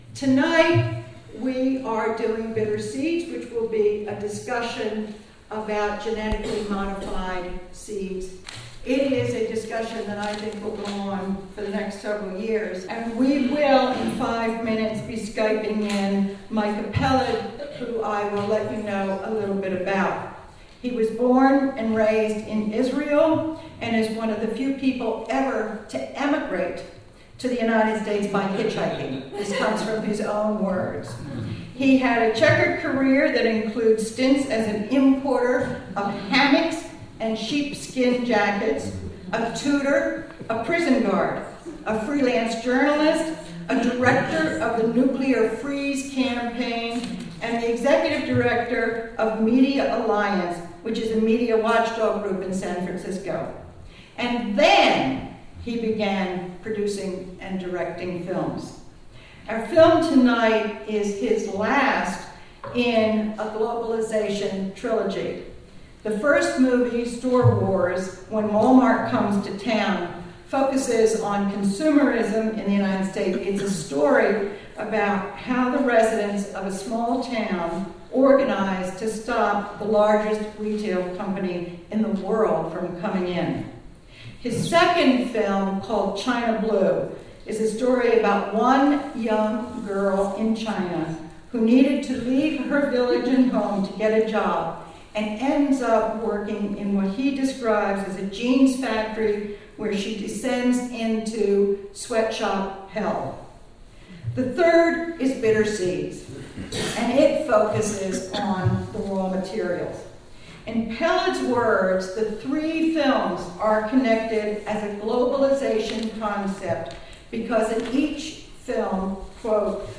Interview
A recording from the Carey Center for Global Good in Rensselaerville, NY on December 1, 2012, during a panel and screening of the film "Bitter Seeds". "Bitter Seeds" examines the causes of an epidemic of farmer suicides in India, and how the the use of genetically modified cotton seeds has caused a fatal debt crisis.